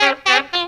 CHEEKY RIFF.wav